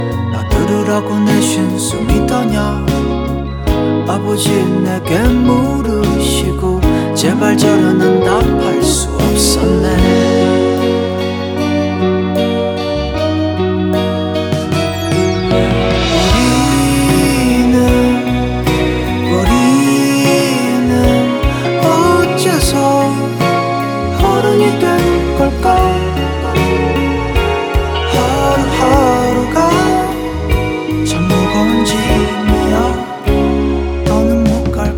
Жанр: Поп музыка / Рок